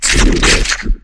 ryuchiChitter5.wav